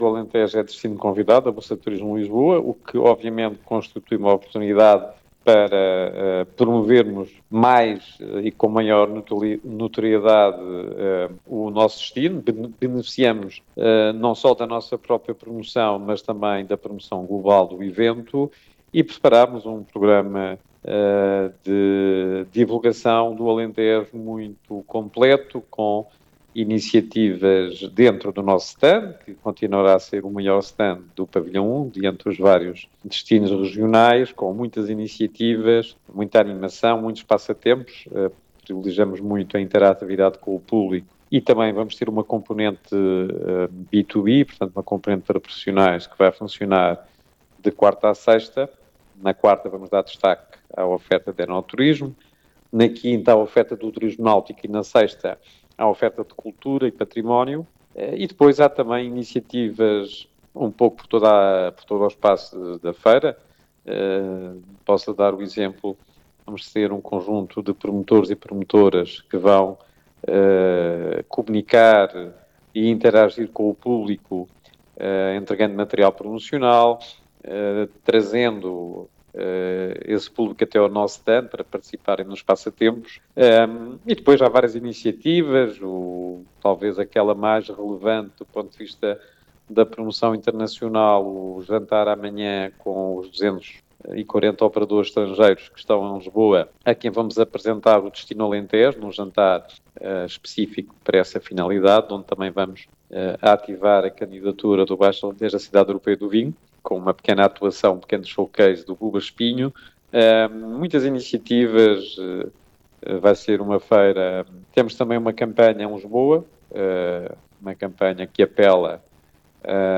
Audio: rádio TDS (entrevista exclusiva)
O presidente da ERT Alentejo e Ribatejo, José Manuel Santos, referiu à TDS a importância da BTL para as regiões